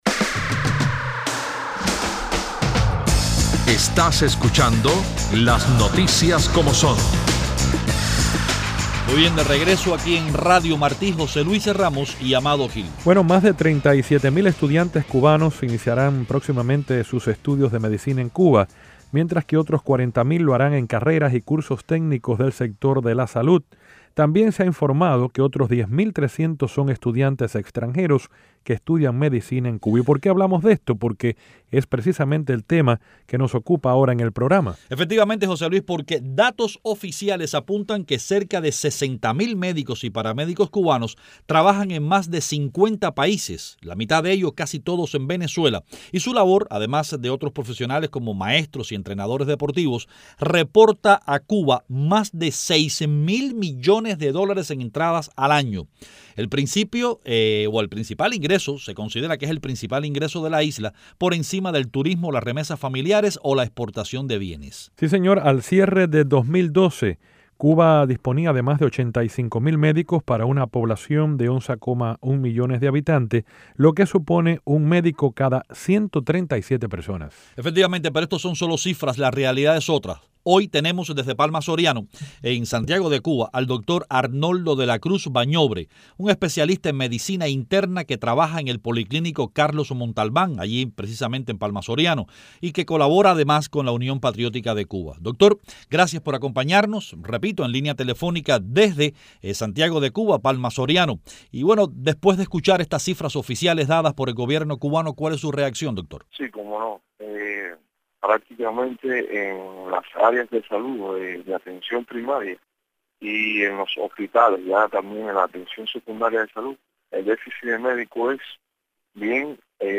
Iniciamos la segunda media hora con una entrevista